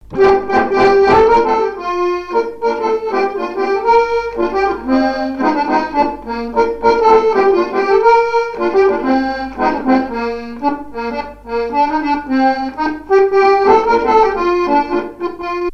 Couplets à danser
branle : courante, maraîchine
airs de danse à l'accordéon diatonique
Pièce musicale inédite